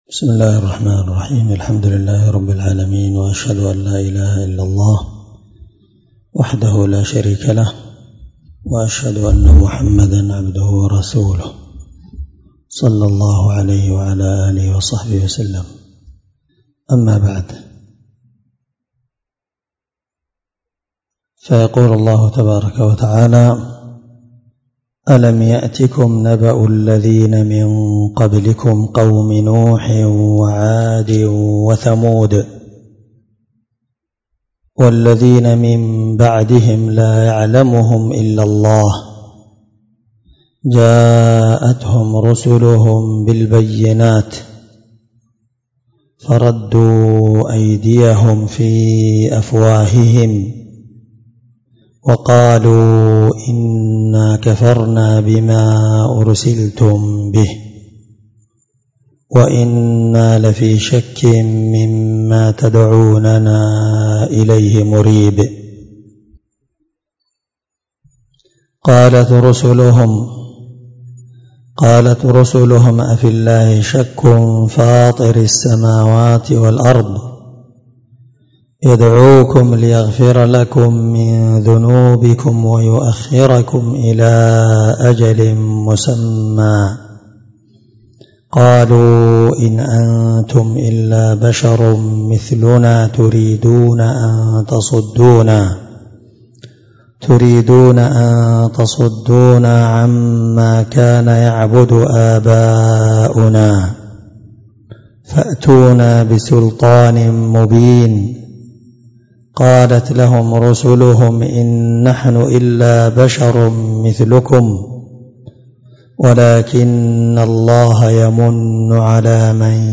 697الدرس 4 تفسير آية (9-12) من سورة إبراهيم من تفسير القرآن الكريم مع قراءة لتفسير السعدي
دار الحديث- المَحاوِلة- الصبيحة.